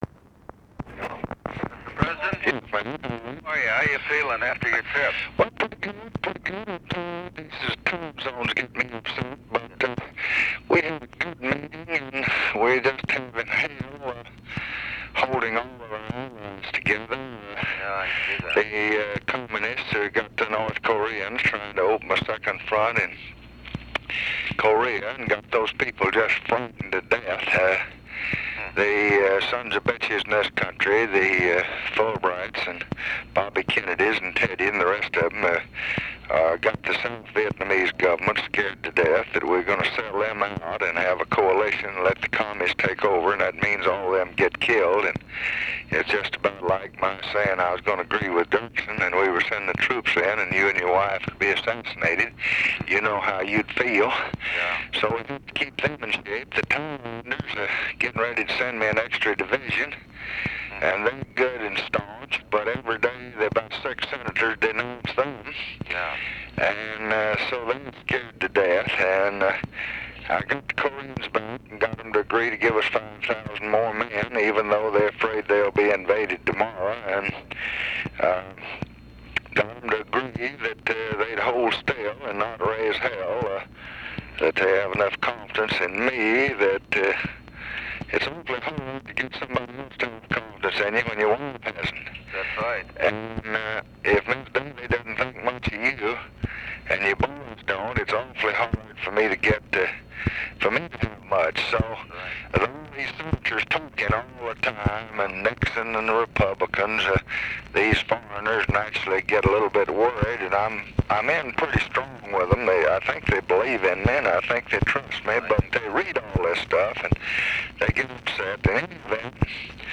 Conversation with RICHARD DALEY, April 19, 1968
Secret White House Tapes